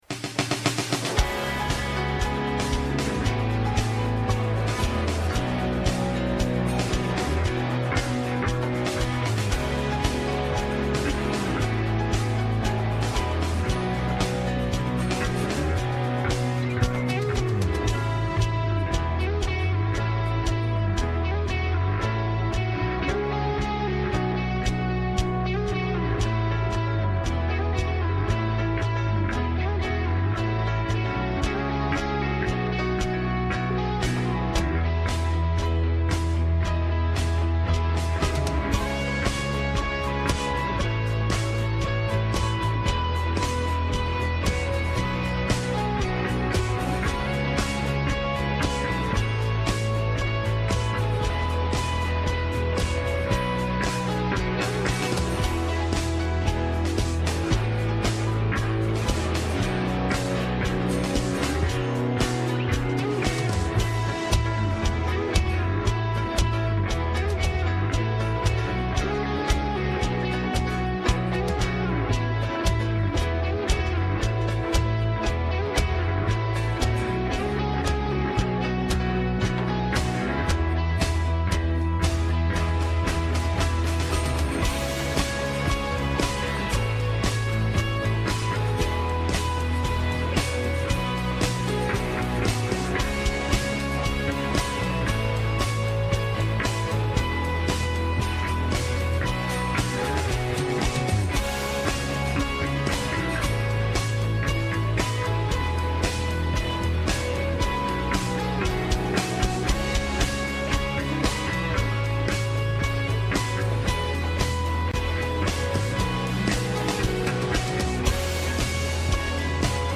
Westgate Chapel Sermons